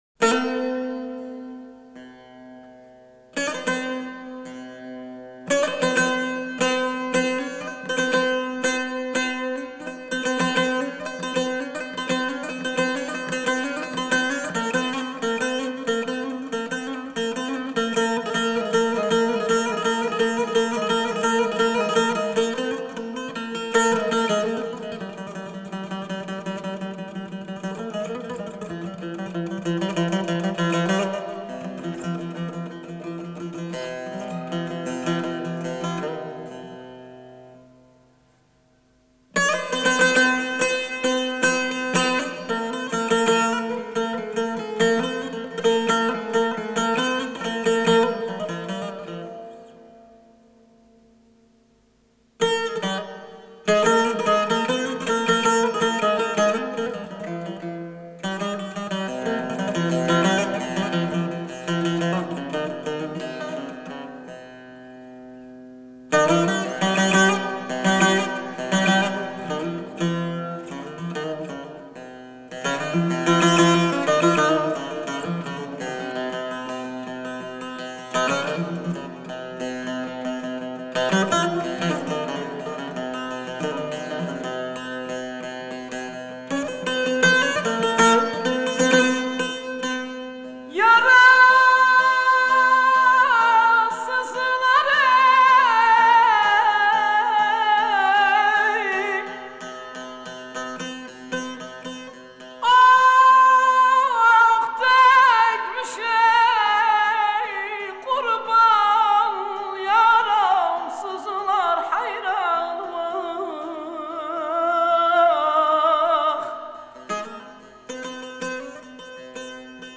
آهنگ ترکی
اهنگ غمگین